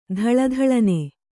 ♪ dhaḷa dhaḷane